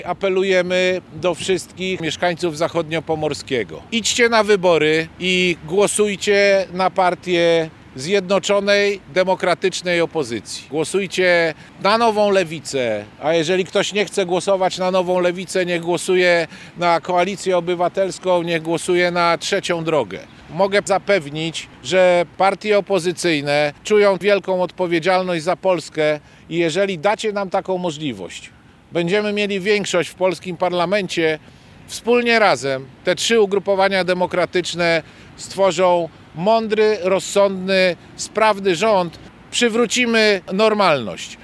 Kandydaci na posłów i senatorów z Nowej Lewicy zwołali konferencję prasową podsumowującą kampanię. Dariusz Wieczorek zachęcał do głosowania na partie opozycji demokratycznej.